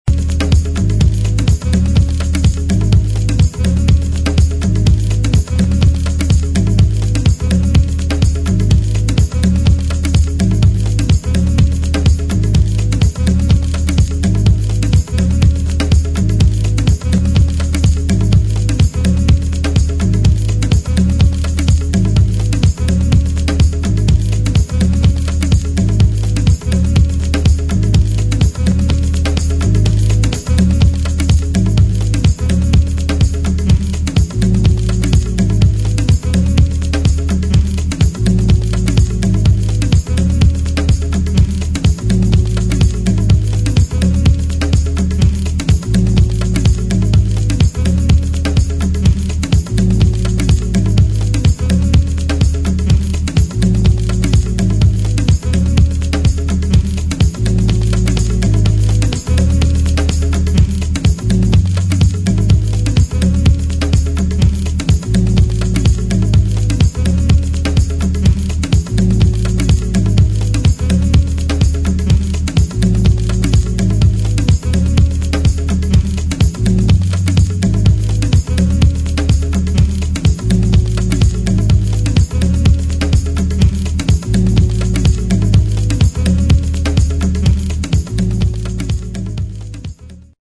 [ DEEP HOUSE / NY HOUSE ]